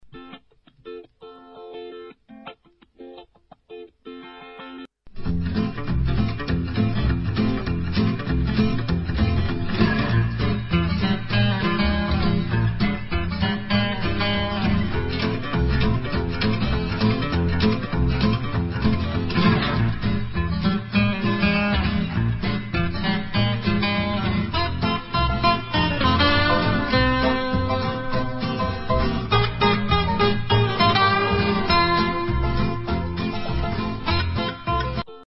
Инстр. анс.